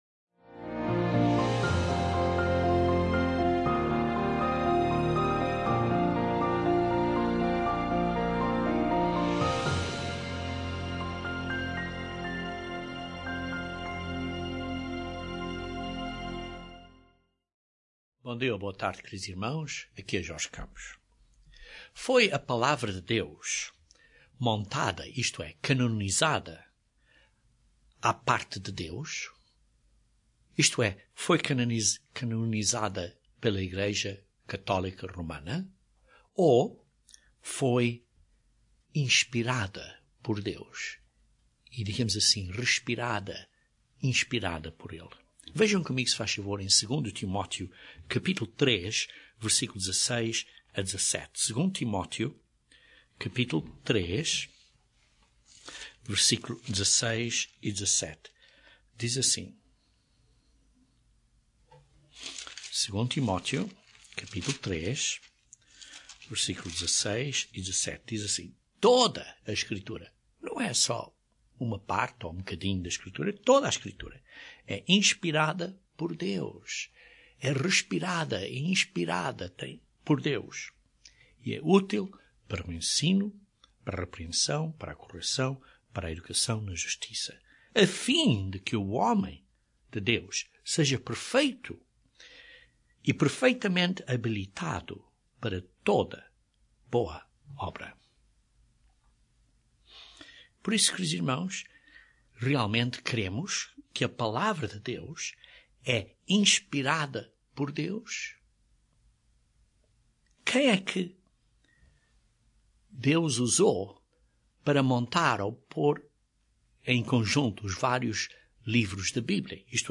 A Bíblia está completa. Este sermão descreve como o Antigo Testamento foi estabelecido e canonizado.